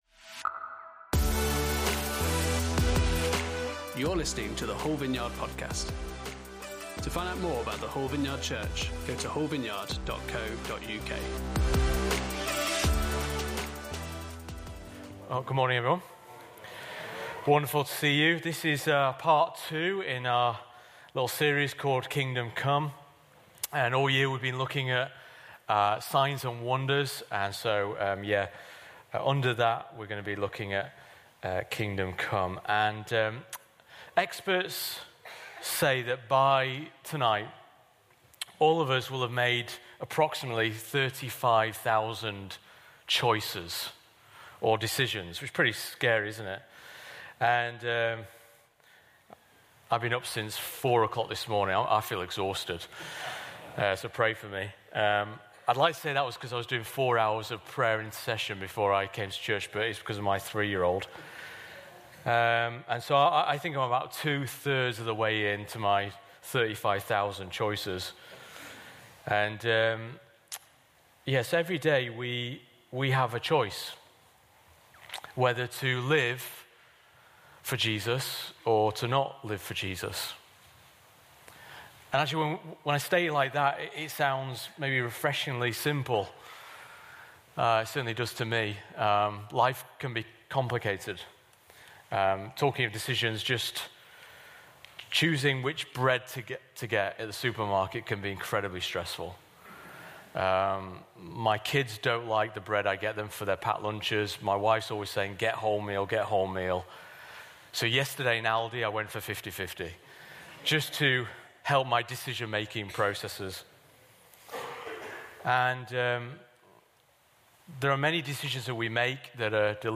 Kingdom Come Service Type: Sunday Service On Sunday morning